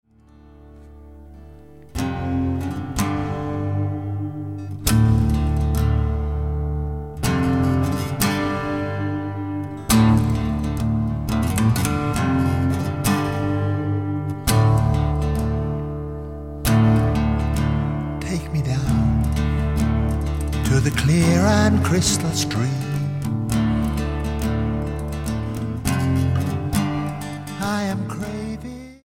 STYLE: Rock
clearly recorded on a budget